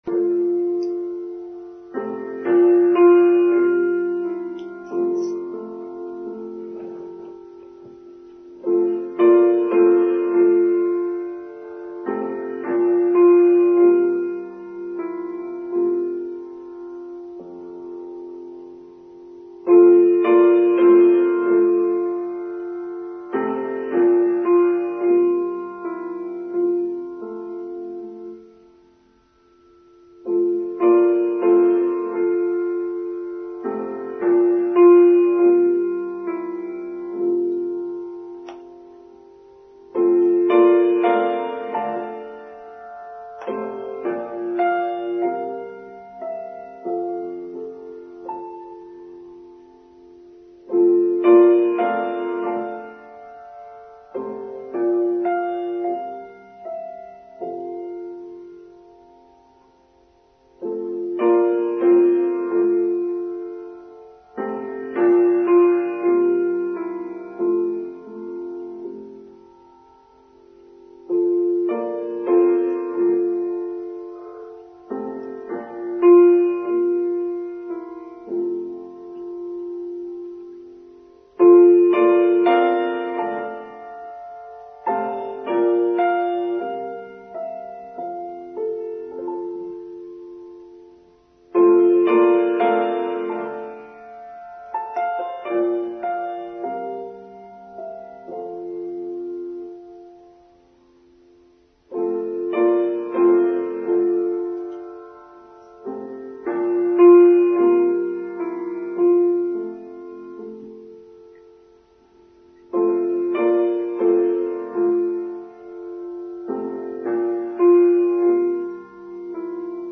A Supernormal Life: Online Service for Sunday 5th March 2023